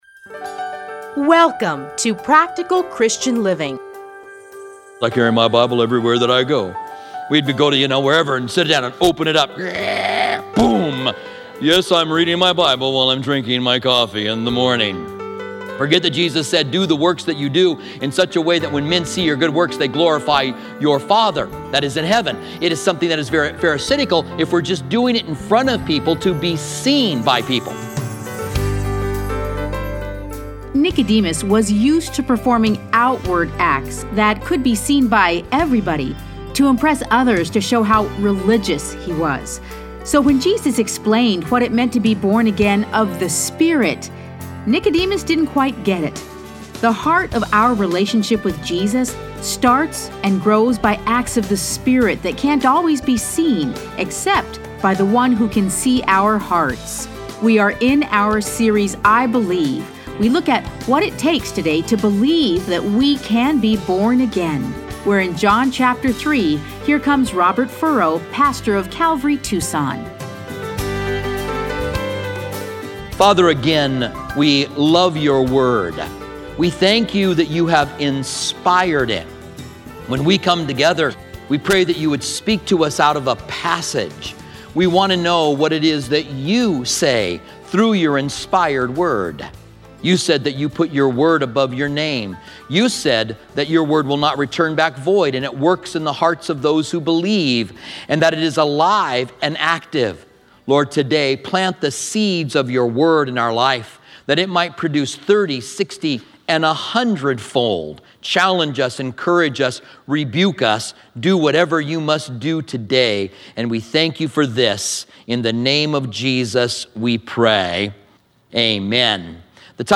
Listen to a teaching from John 3:1-14.